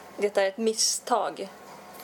間違ってる(MACHIGI/MACHIGATTERU) 　 Mistake／It makes a mistake. Det är eH Misstag (ミスットウォーグ)